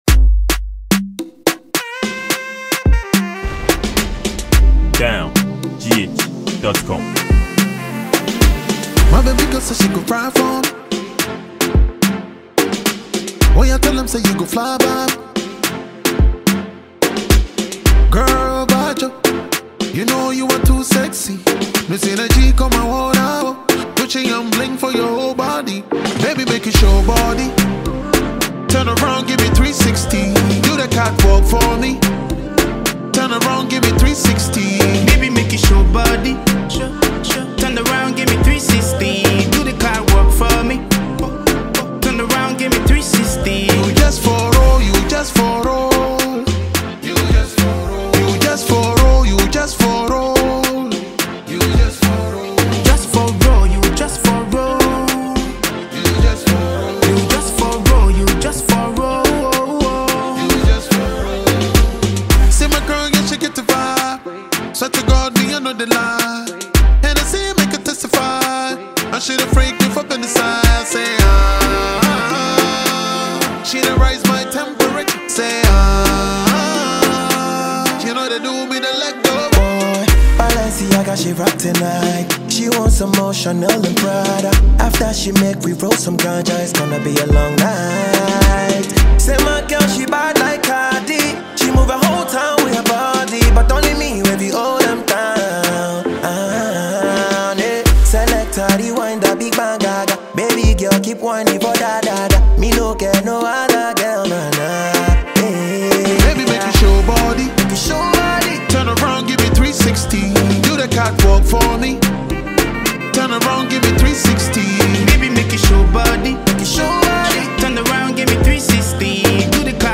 Libarian afrobeat musician